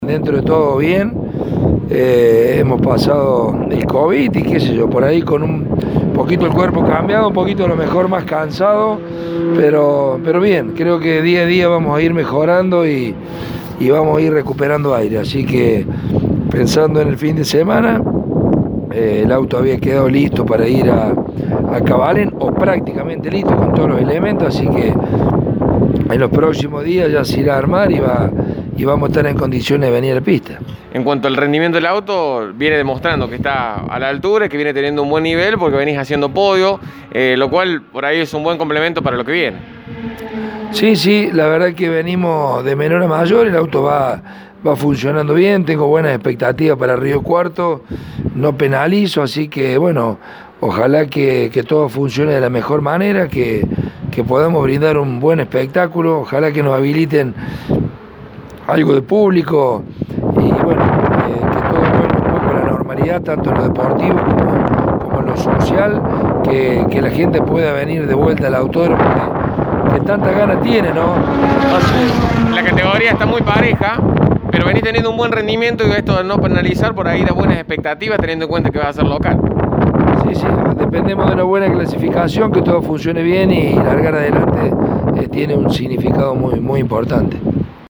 En diálogo con Poleman Radio, nos contó sus expectativas al respecto: